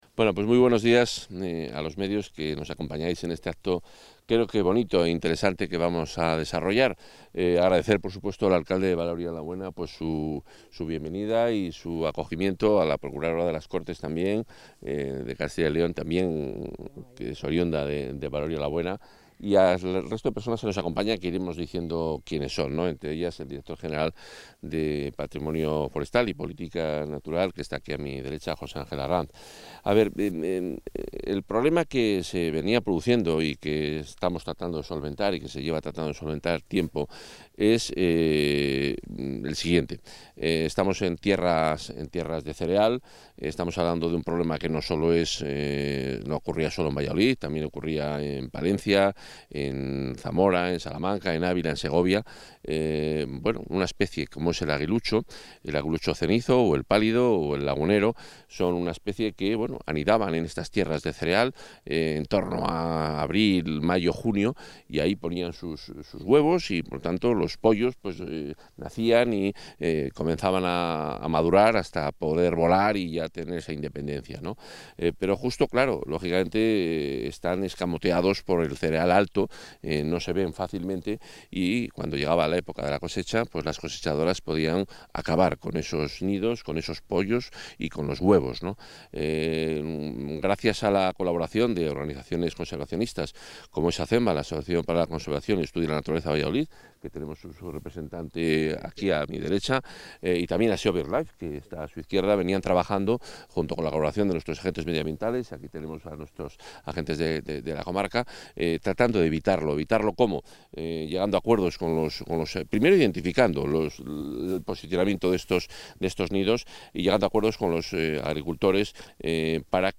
Declaraciones del consejero.
El consejero de Medio Ambiente, Vivienda y Ordenación del Territorio, Juan Carlos Suárez-Quiñones, ha participado esta mañana en la suelta de aguiluchos realizada en Valoria la Buena (Valladolid), en el marco del convenio de colaboración firmado recientemente con la Asociación para la Conservación y Estudio de la Naturaleza de Valladolid (Acenva) y la Sociedad Española de Ornitología (SEO/BirdLife), para la coordinación y colaboración de los trabajos realizados por estas organizaciones y por la Junta en favor de la conservación de los aguiluchos en Castilla y León.